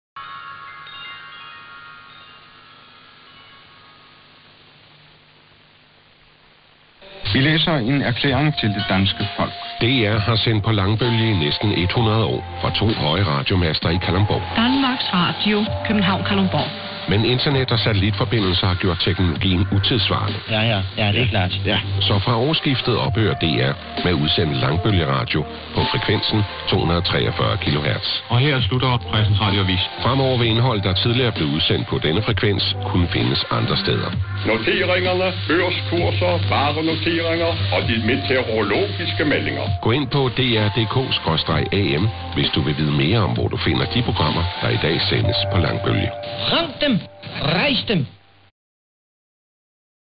Some announcement begun airing on 243 kHz after interval signals, here’s the recording [about the closure of Kalundborg AM / Ed]: